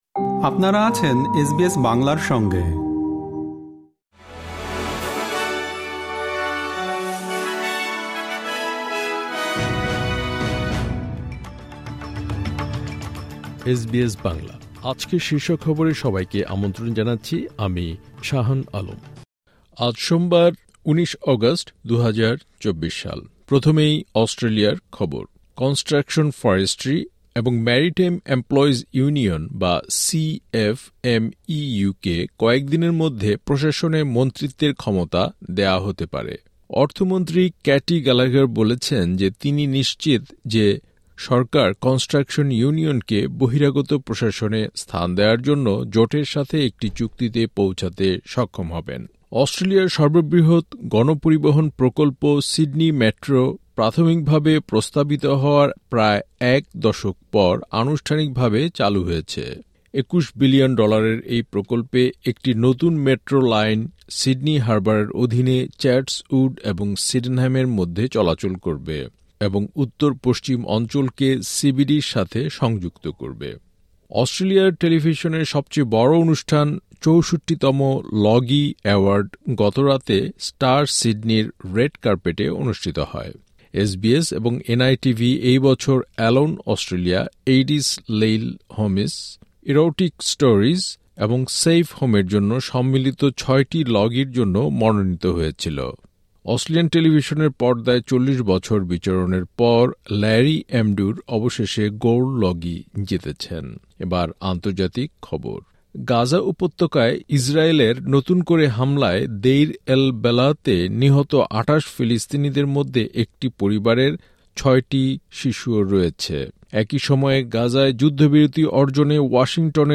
এসবিএস বাংলা শীর্ষ খবর: ১৯ অগাস্ট, ২০২৪